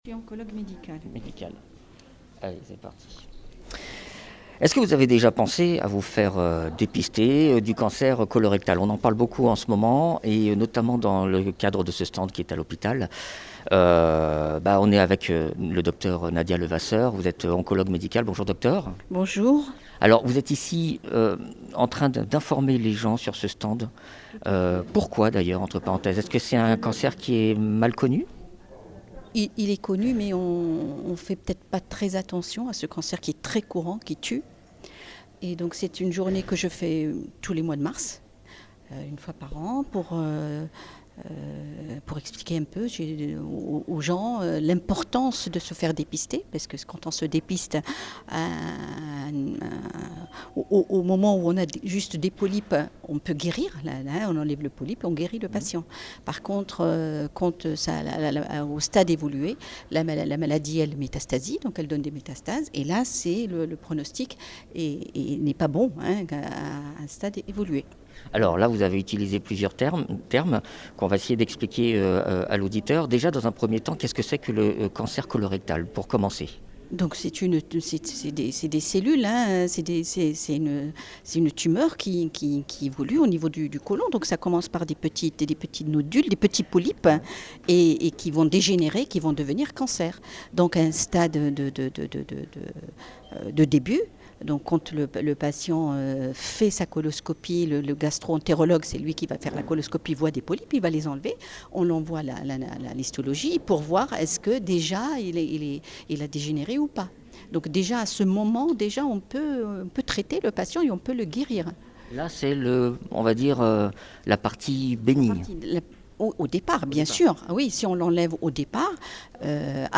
Un médecin oncologue nous explique comment prévenir son arrivée.
Interviews